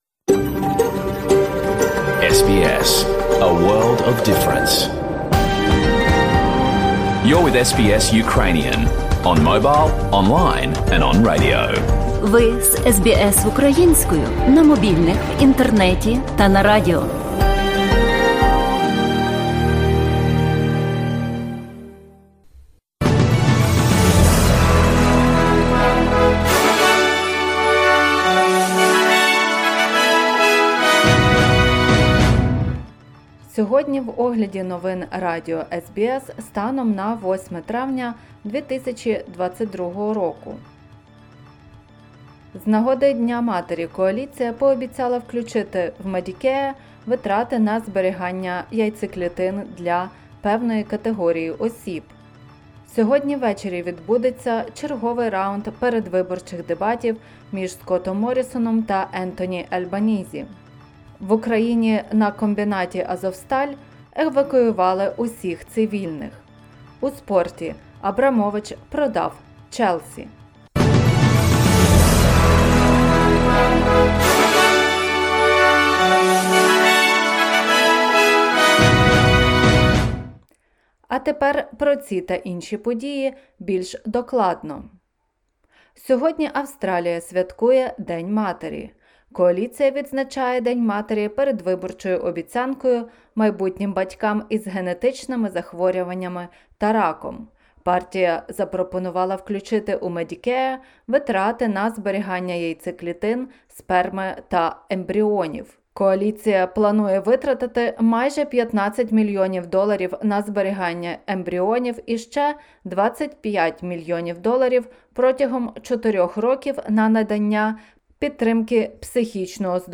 SBS news in Ukrainian - 8/05/2022